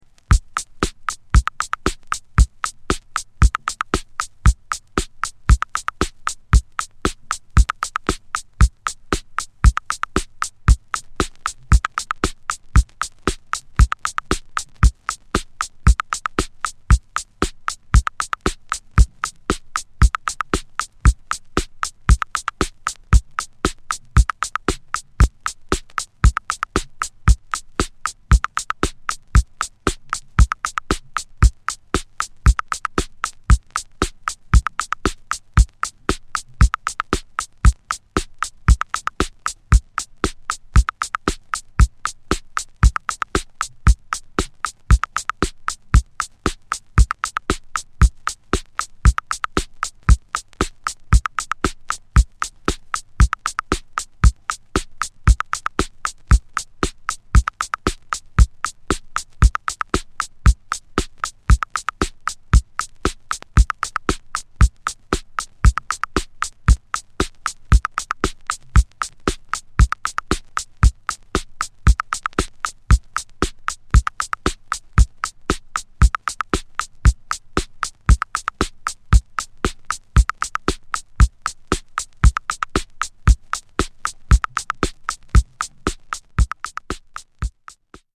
rhythm box tracks that are DJ tools